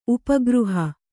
♪ upa gřha